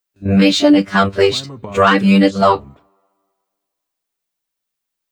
“Mission accomplished, drive unit locked” Clamor Sound Effect
Can also be used as a car sound and works as a Tesla LockChime sound for the Boombox.